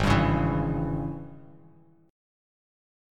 AbM7sus4#5 chord